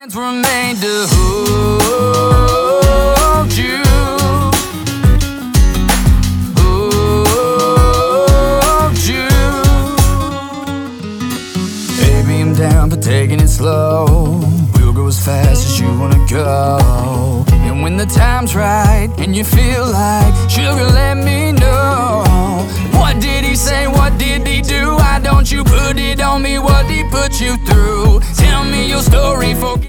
• Singer/Songwriter